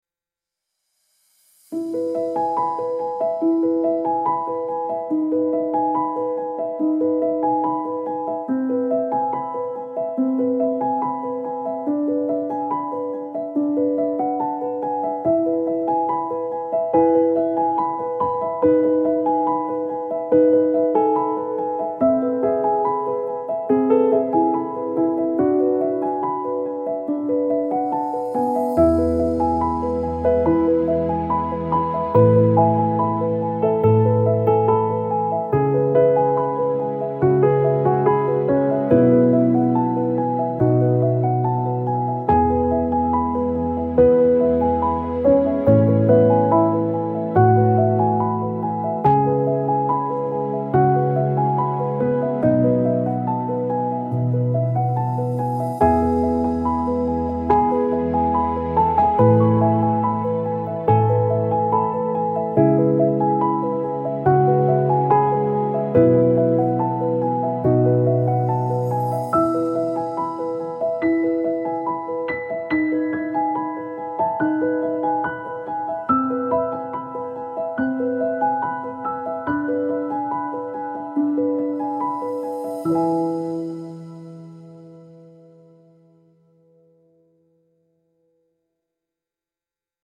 tranquil flowing piano piece with warm reverb and soothing atmosphere